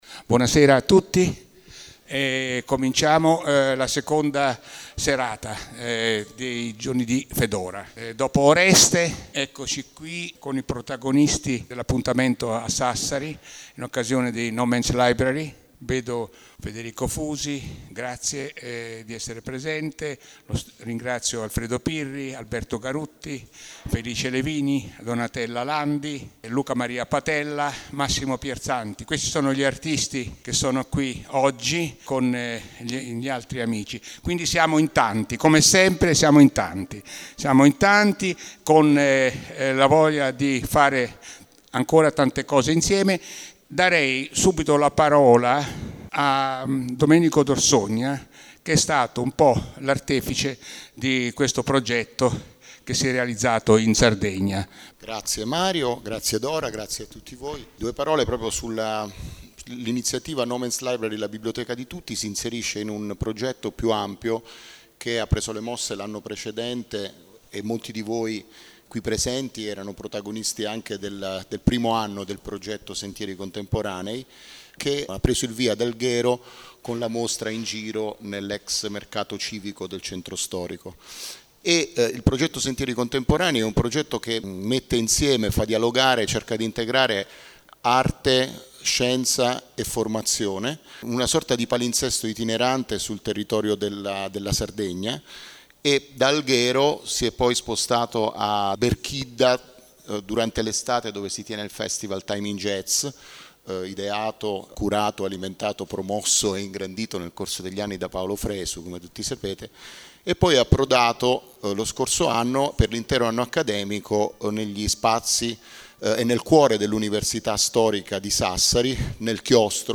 No Man’s Library / La Biblioteca di Tutti – Presentation of the book